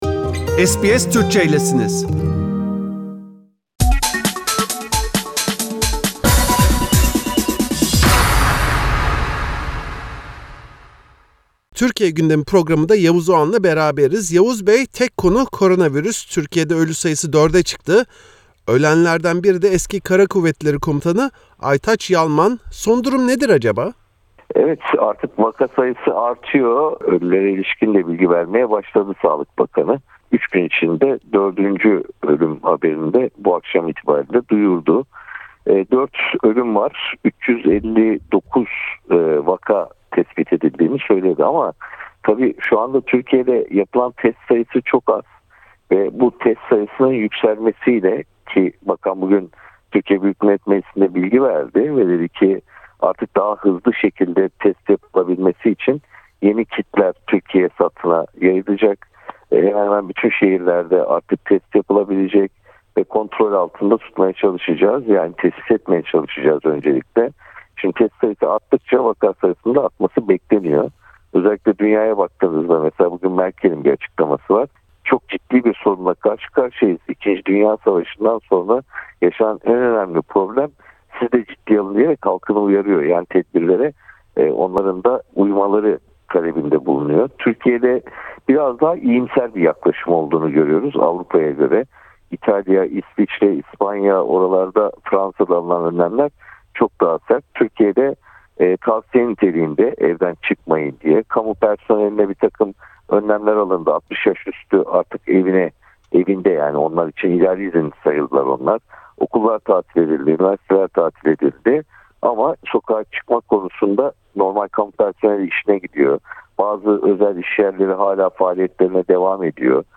SBS Türkçe’ye Türkiye’deki koronavirüs salgınıyle mücadele çalışmalarını değerlendiren gazeteci Yavuz Oğhan, Sağlık Bakanı Fahrettin Koca’nın takdir gördüğünü söyledi. Ayrıca, Avustralya’nın aksine, bir günlük bir alışveriş paniği sonrası her şeyin normale döndüğünü belirtti.